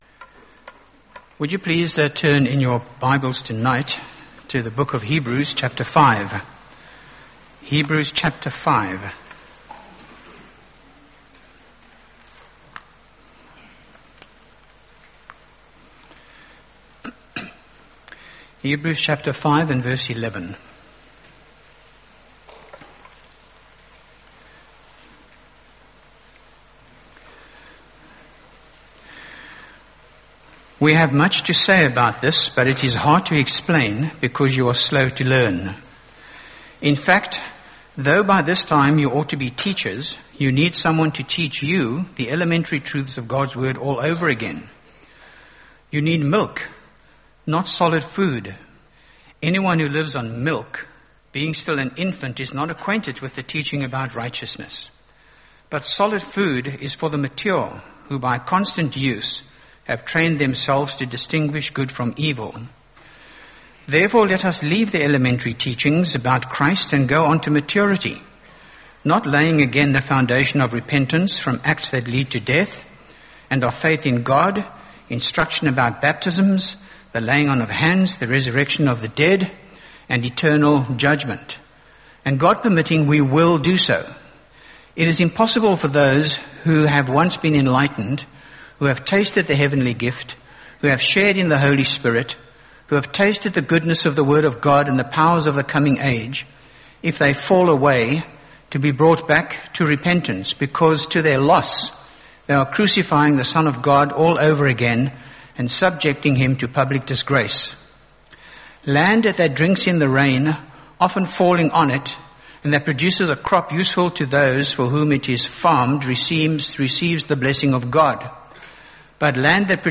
by Frank Retief | Jan 21, 2025 | Frank's Sermons (St James) | 0 comments